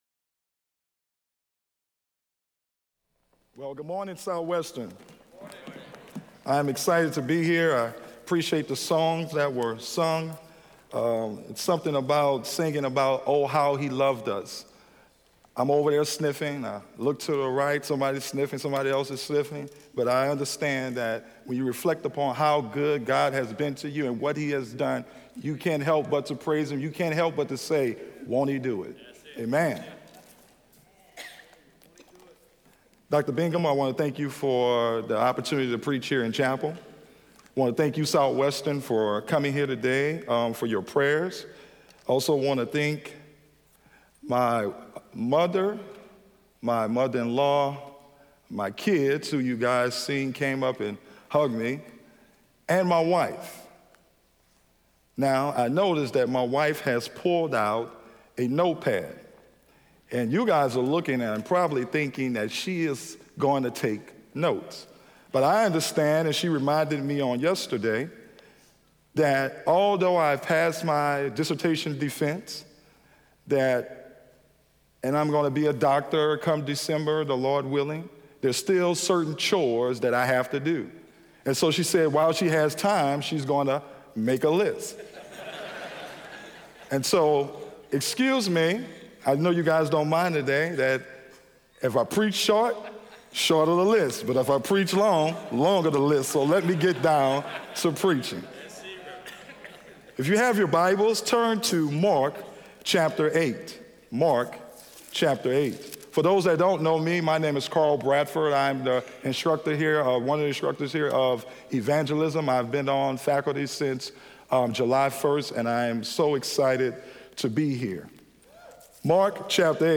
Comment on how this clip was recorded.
in SWBTS Chapel on Thursday October 11, 2018